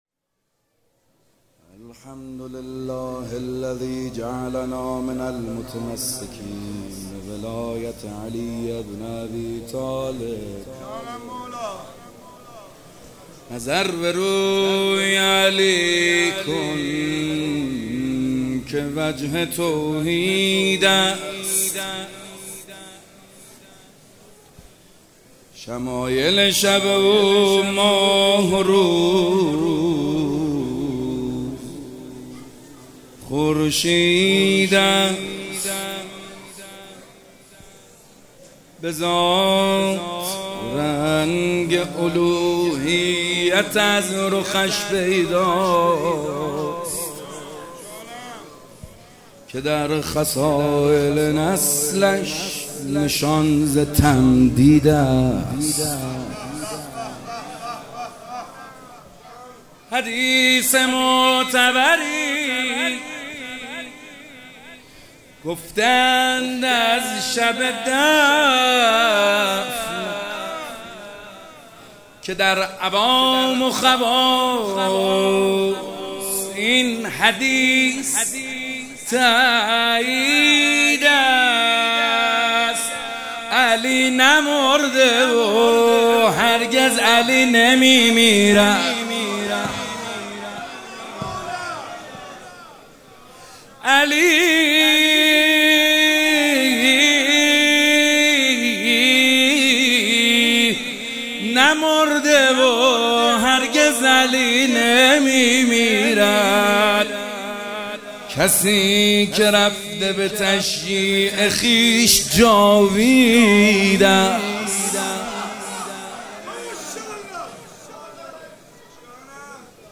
مداحی نظر به روی علی کن که وجه توحید است توسط محمد حسین حدادیان در مجلس هیئت رزمندگان غرب تهران | 30 اردیبهشت | 1403 اجراشده. مداحی به سبک مدح اجرا شده است.